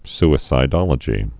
(sĭ-sī-dŏlə-jē)